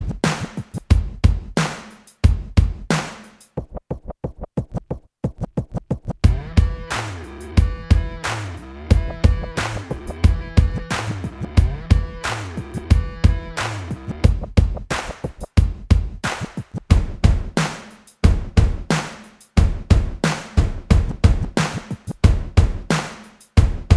Minus All Guitars Pop (1990s) 3:05 Buy £1.50